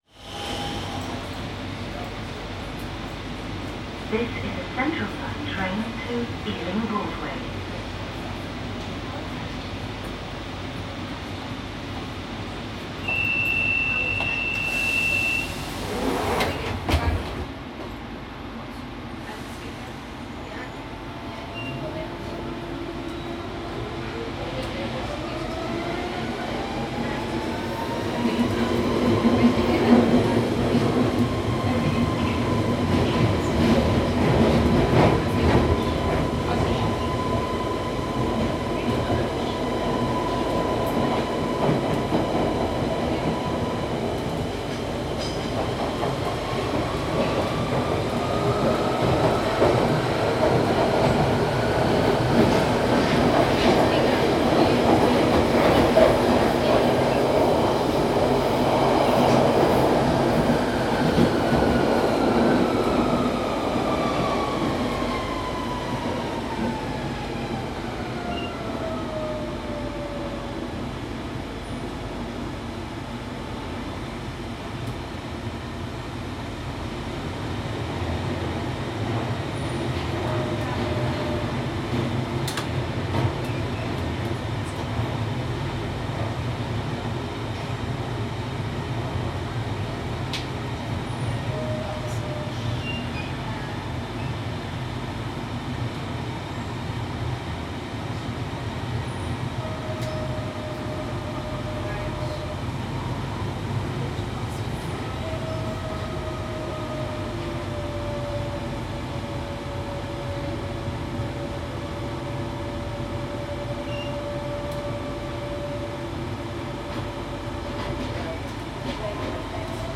Field recording from the London Underground